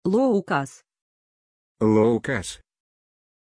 Aussprache von Loukas
pronunciation-loukas-ru.mp3